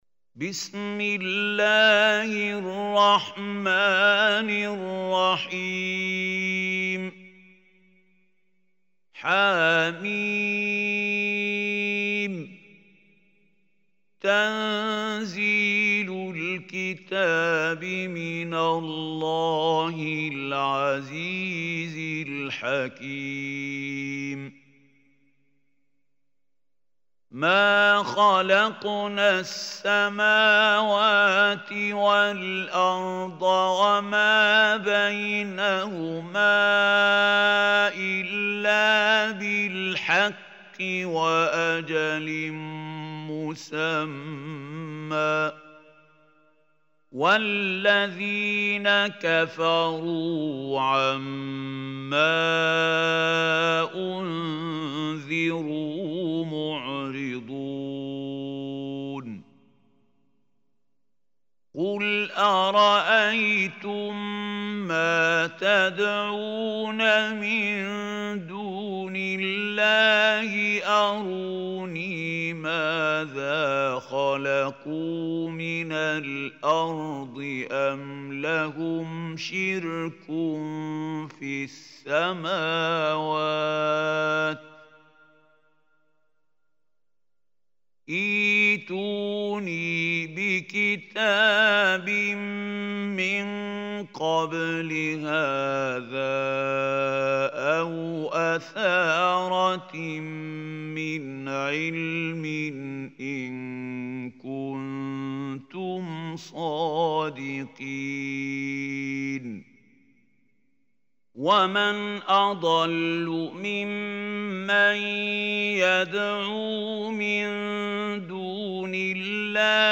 Surah Ahqaf Recitation MP3 by Khalil Hussary
Surah Ahqaf, is 46 surah of Holy Quran. Listen or play online mp3 tilawat / recitation in Arabic in the beautiful voice of Sheikh Mahmoud Khalil Al Hussary.